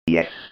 جلوه های صوتی
دانلود صدای ربات 7 از ساعد نیوز با لینک مستقیم و کیفیت بالا